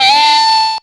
SQUEELER.wav